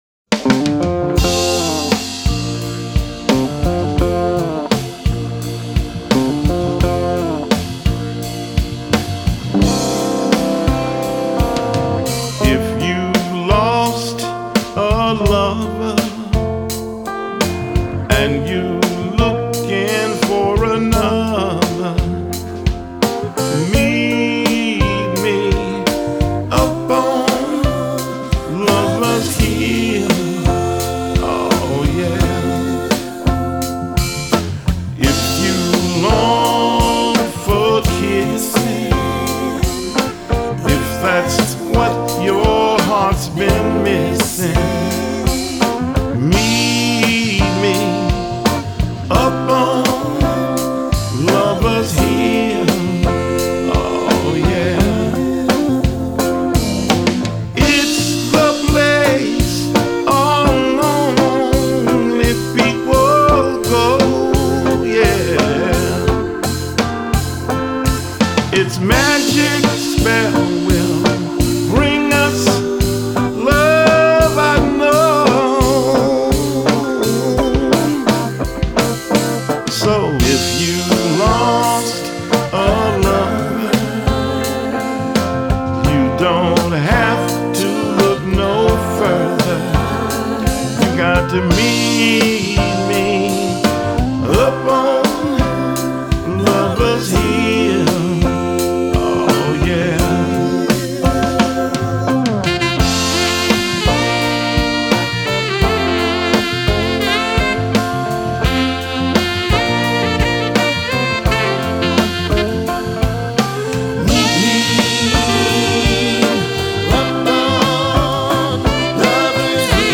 lovely romantic ballads